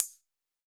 UHH_ElectroHatA_Hit-06.wav